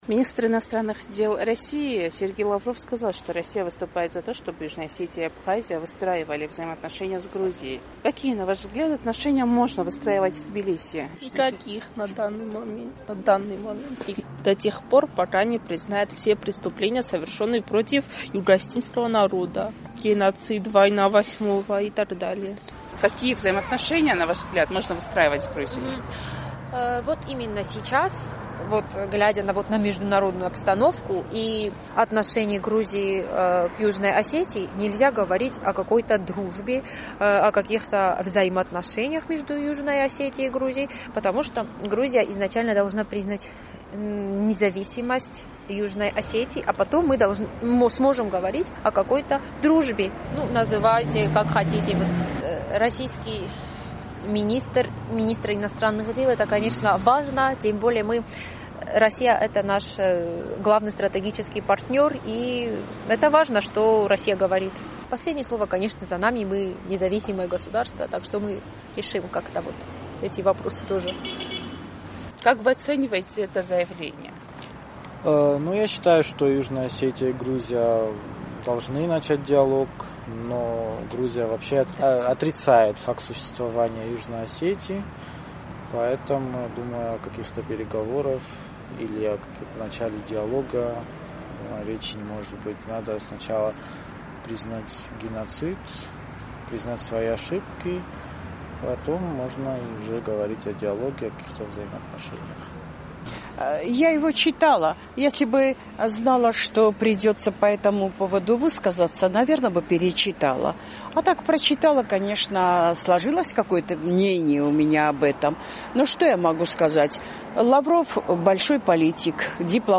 Министр иностранных дел России Сергей Лавров сказал в своем заявлении о том, что Россия выступает за то, чтобы Южная Осетия и Абхазия выстраивали свои отношения с Грузией. «Эхо Кавказа» поинтересовалось у жителей Южной Осетии, как они оценивают это заявление и какие взаимоотношения можно выстраивать с Тбилиси?